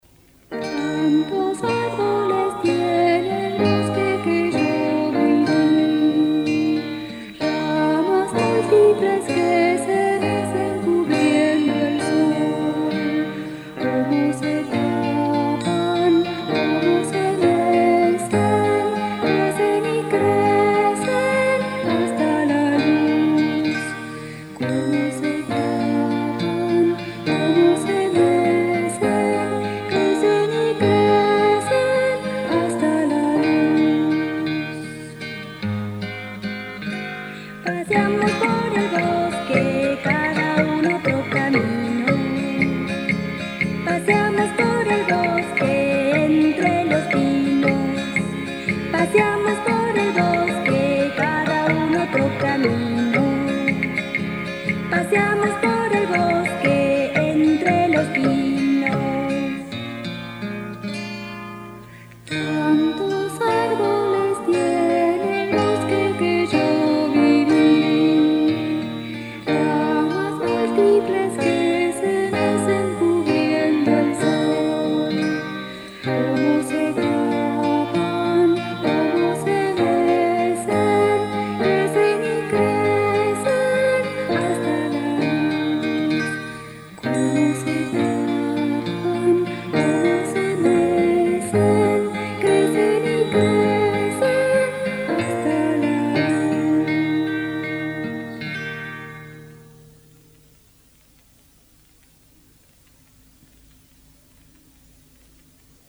La primera parte es ternaria y la segunda es binaria.